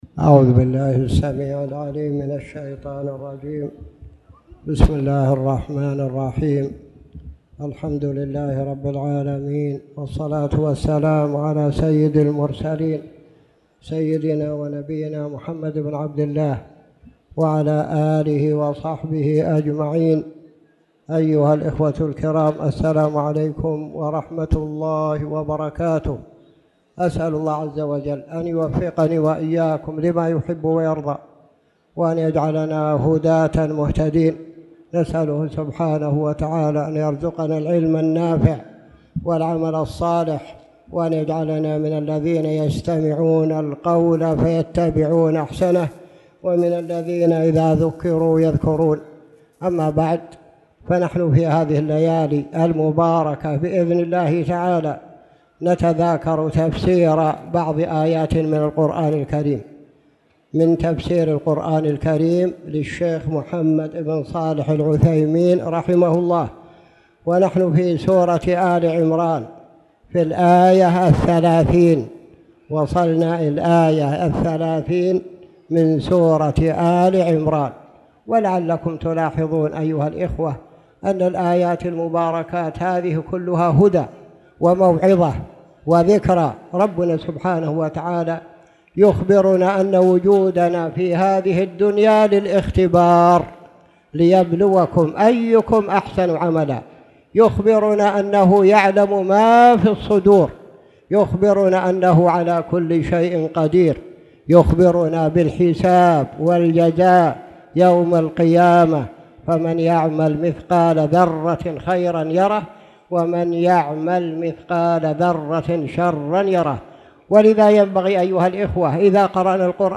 تاريخ النشر ٣ جمادى الأولى ١٤٣٨ هـ المكان: المسجد الحرام الشيخ